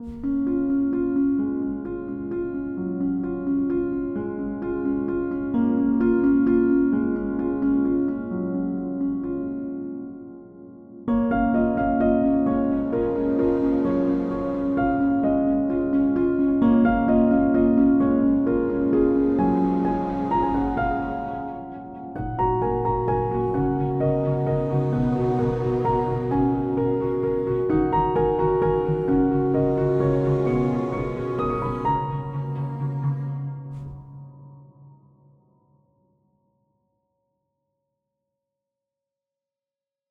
A collection of symphonic tracks